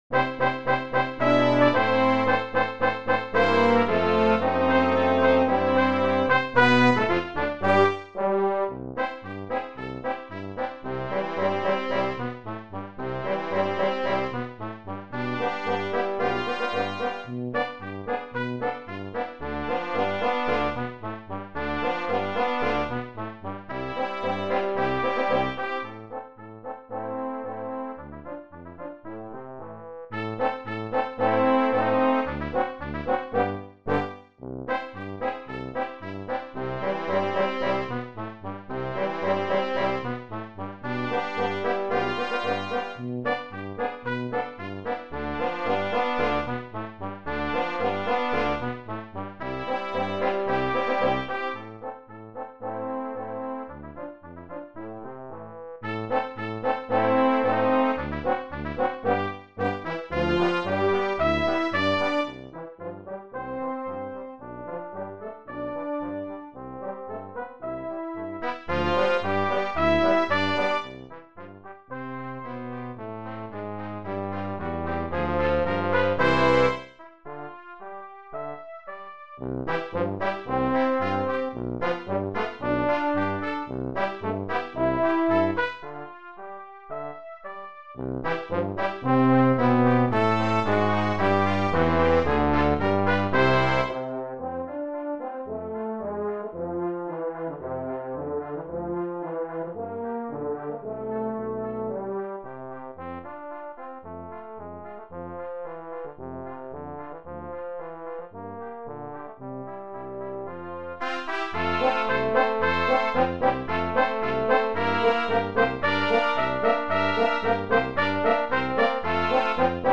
Brass Quintet
Marches included in this collection: